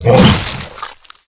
Monster Bite.wav